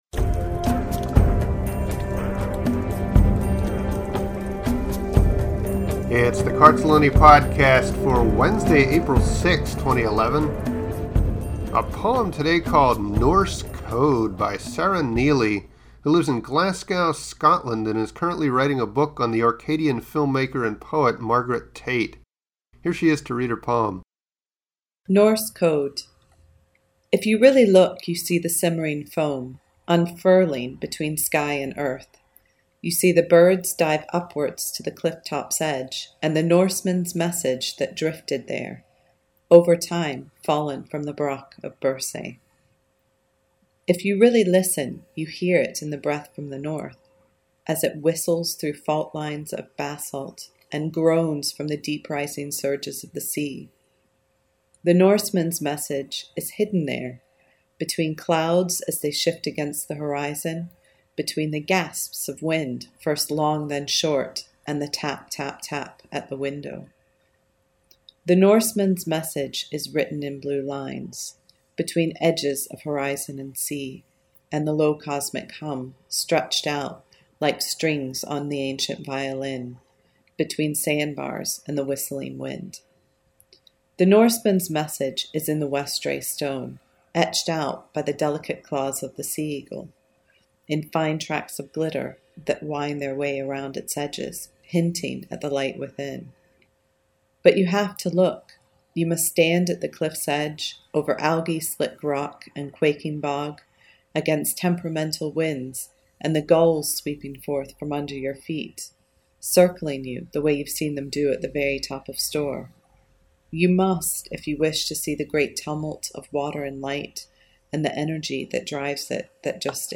Lovely atmospheric poem and beautifully read!